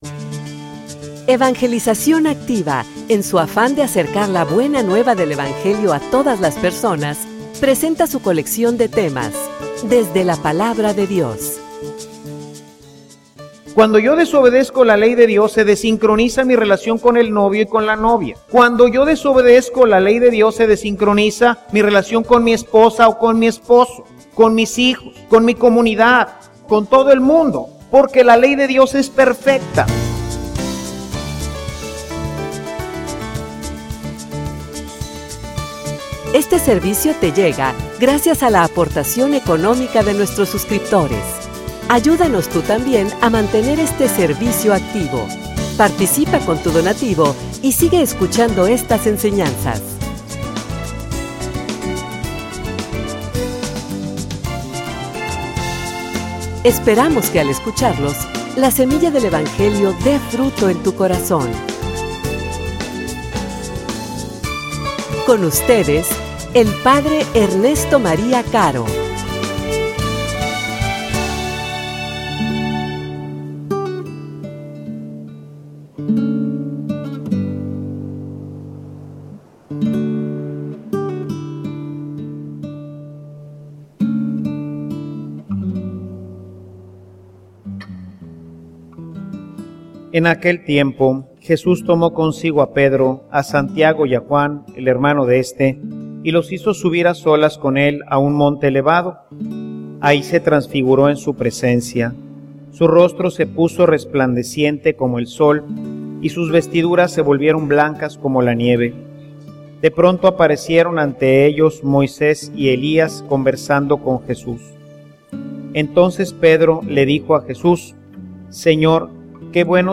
homilia_Escucha_y_obediencia.mp3